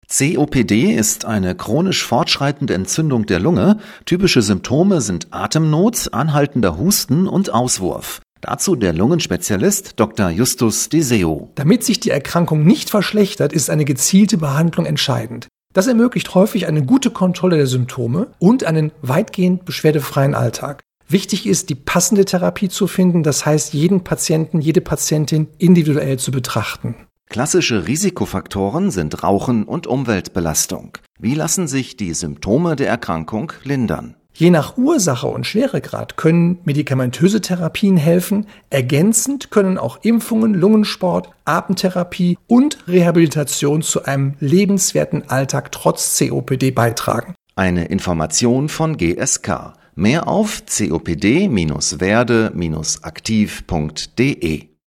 rts-beitrag-chronische-lungenerkrankung.mp3